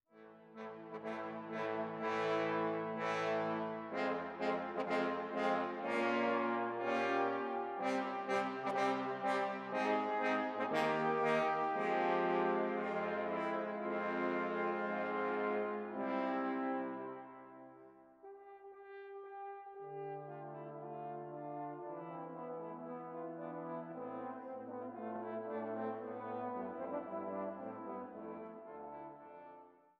Posaunenquartett